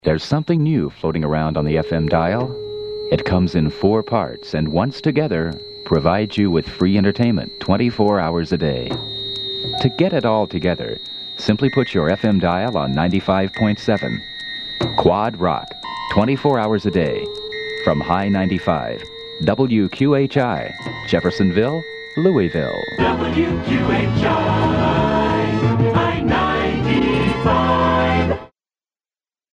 WQHI TV Spot Audio - Revised
WQHITVSpotAudioRevised.mp3